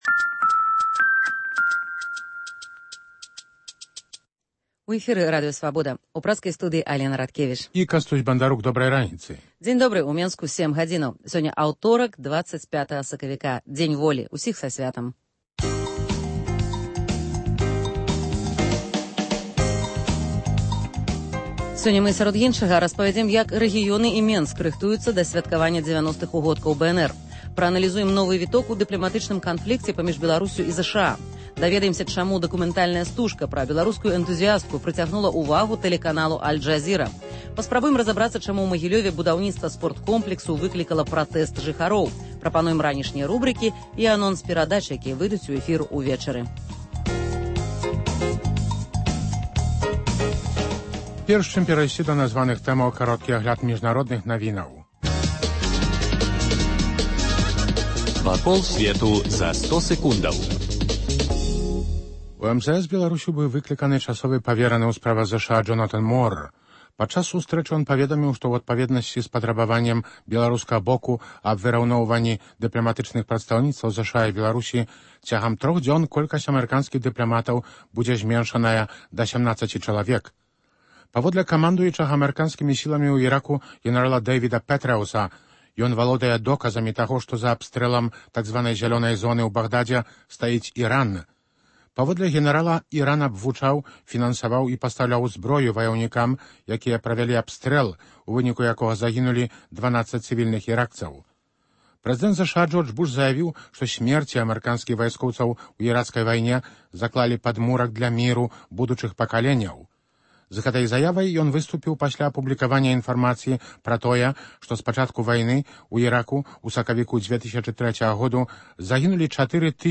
Ранішні жывы эфір
* Што адбываецца ў рэгіёнах і ў сталіцы напярэдадні сьвяткаваньня 90-х угодкаў БНР? * Наўпроставы эфір з Анатолем Лябедзькам. * У аддаленым магілёўскім мікрараёне на месцы даўняга рынку хочуць пабудаваць спорткомплекс.
* Бліц-аналіз: дачыненьні РБ і ЗША.